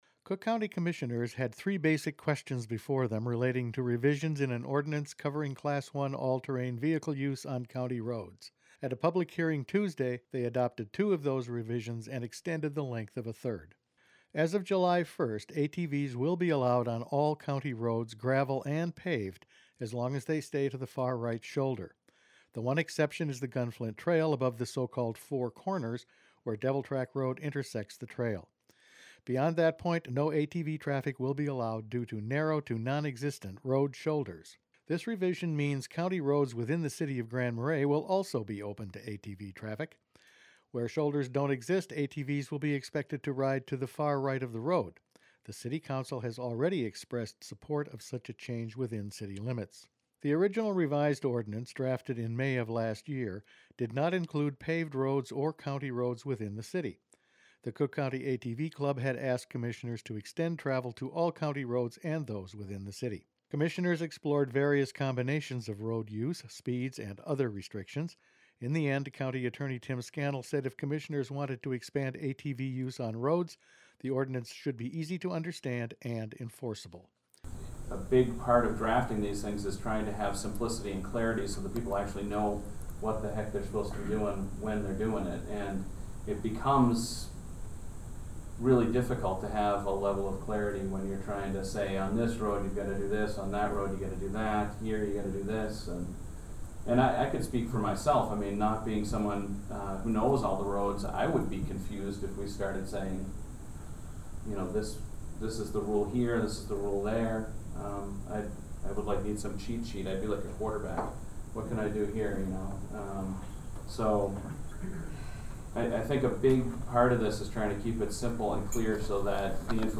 The ordinance public hearing took over an hour of discussion, with many ATV Club members present.
you can have a sign tomorrow (laughter).